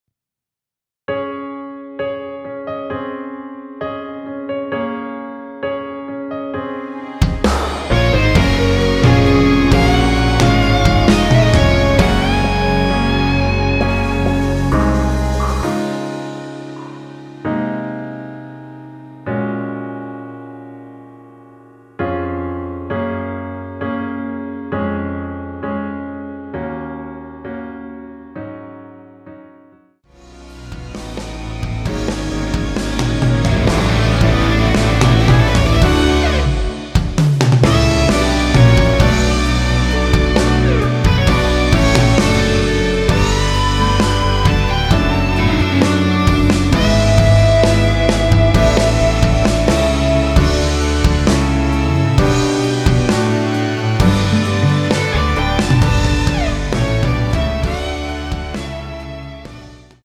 MR입니다.
Db
앞부분30초, 뒷부분30초씩 편집해서 올려 드리고 있습니다.
중간에 음이 끈어지고 다시 나오는 이유는